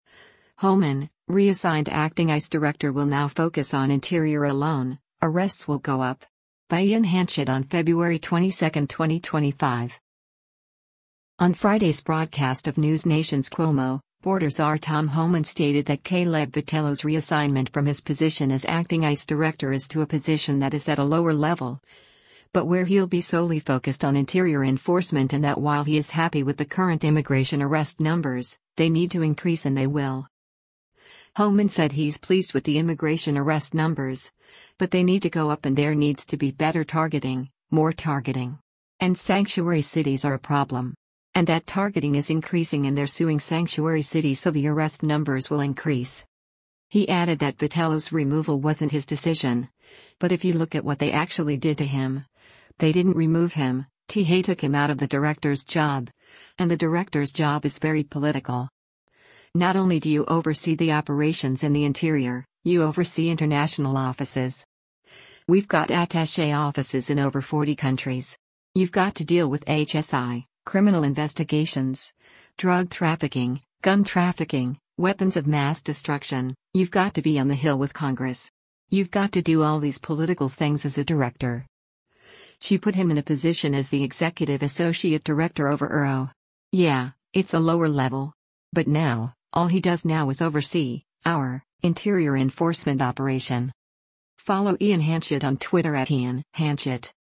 On Friday’s broadcast of NewsNation’s “Cuomo,” Border Czar Tom Homan stated that Caleb Vitello’s reassignment from his position as acting ICE Director is to a position that is at a lower level, but where he’ll be solely focused on interior enforcement and that while he is happy with the current immigration arrest numbers, they need to increase and they will.